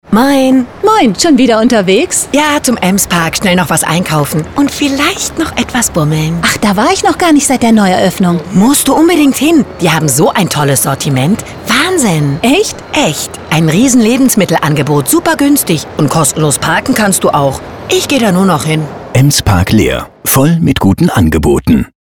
Funkspot "Herbst"